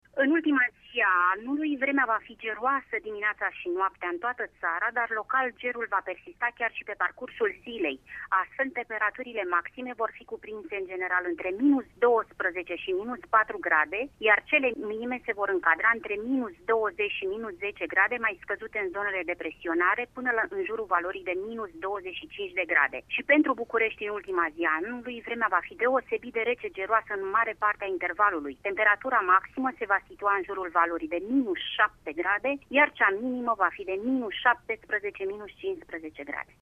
Cum va fi vremea la sfârşitul anului, ne spune meteorologul
meteorolog.mp3